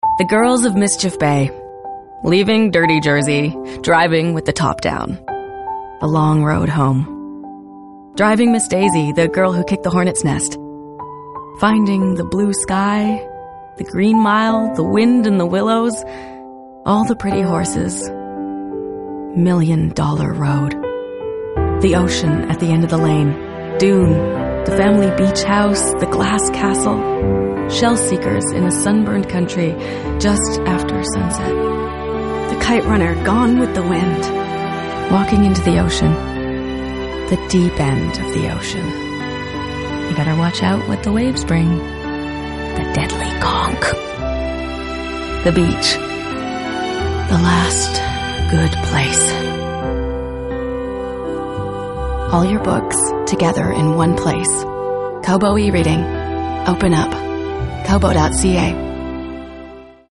GoldRadio - Campaign